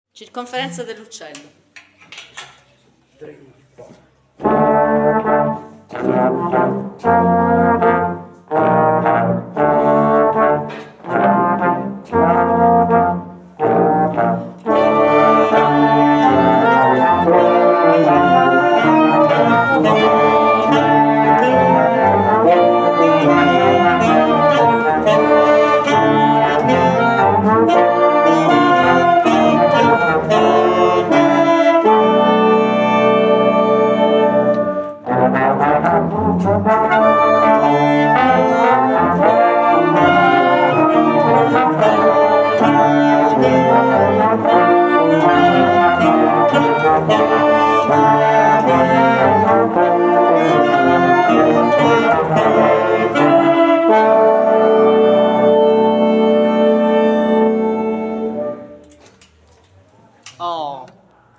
Prove